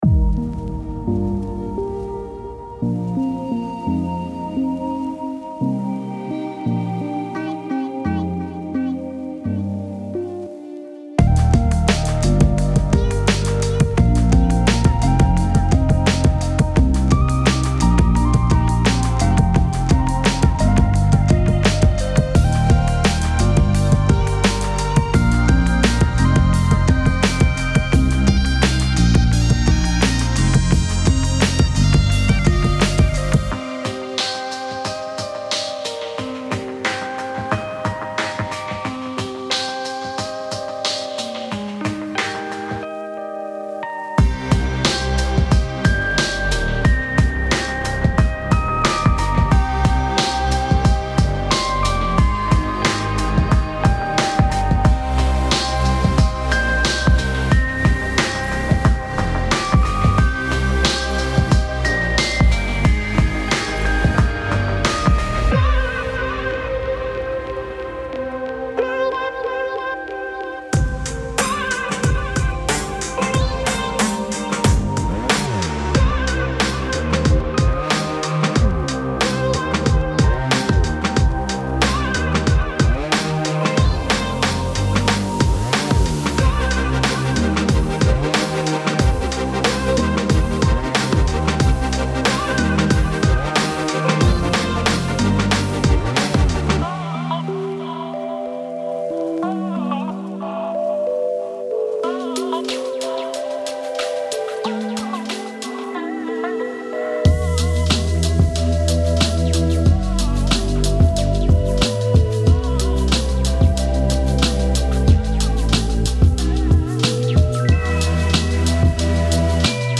• 029 x Synth Loops - (Leads And Pads)